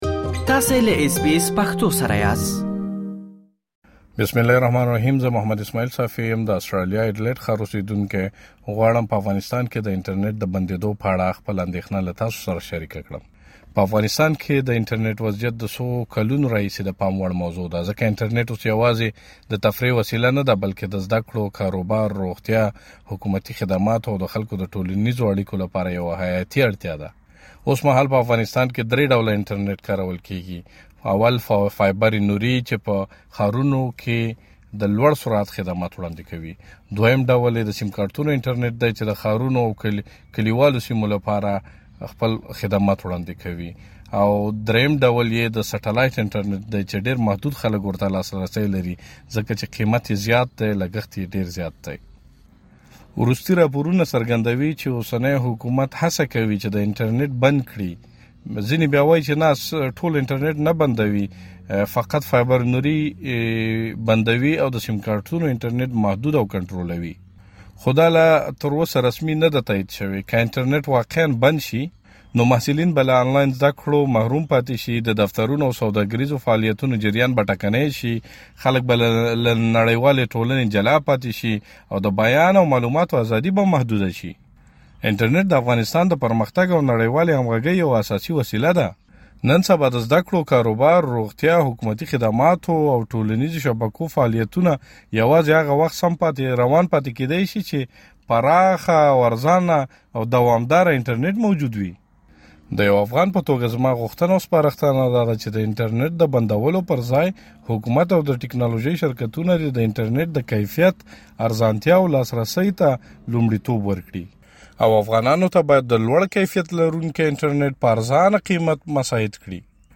طالبانو د افغانستان په یو شمېر ولایتونو کې د نوري فایبر په خدمتونو بندیز لګولی دی. په آسټرالیا کې یو شمېر مېشتو افغانان له اس بي اس پښتو سره په خبرو کې دغه کار غندلی او ژوره اندېښنه یې څرګنده کړې. په آسټرالیا کې د مېشتو افغانانو نظرونه دلته اورېدلی شئ.